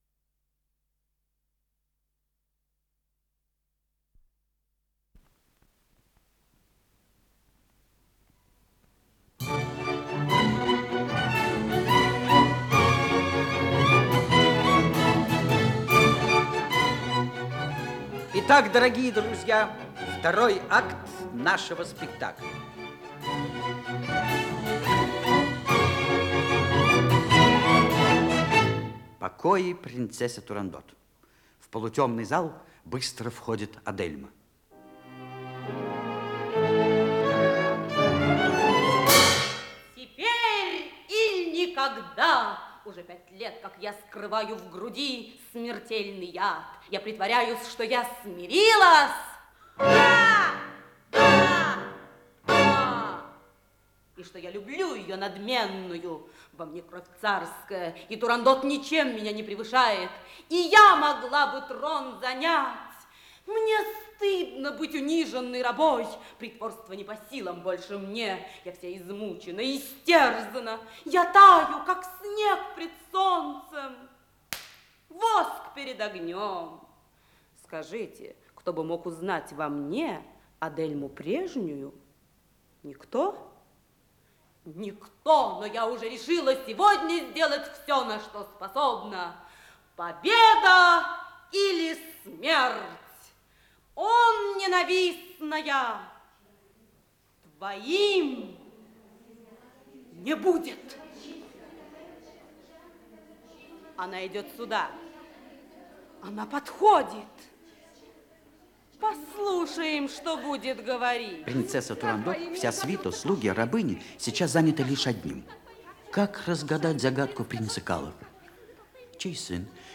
Исполнитель: Артисты и оркестр театра
Название передачи Принцесса Турандот Подзаголовок Сказочная трагикомедия, радиокомпозиция спектакля Государственного Академического театра им.